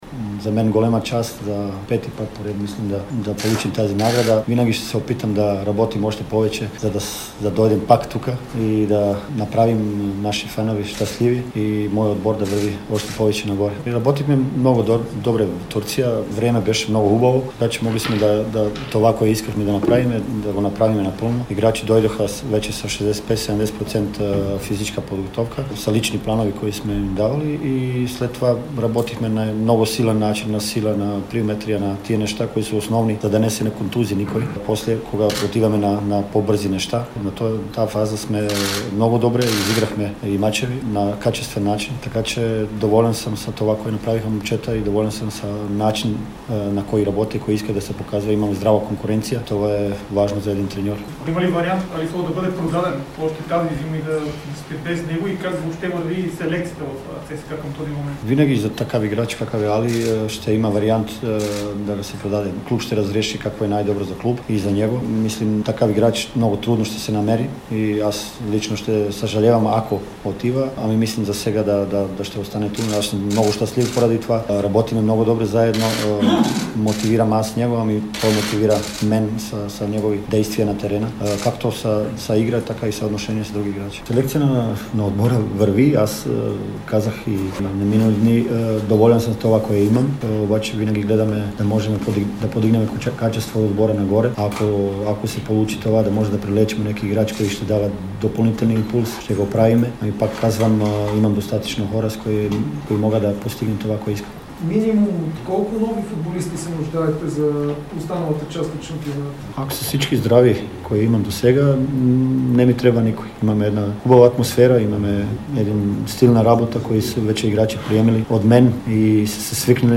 След като получи наградата, наставникът на „армейците“ говори пред медиите. Той призна, че ще е трудно за ЦСКА, ако нападателят Али Соу напусне клуба, защото футболисти като него трудно се намират. Акрапович бе категоричен, че е доволен от подготовката, която проведе тимът в Турция.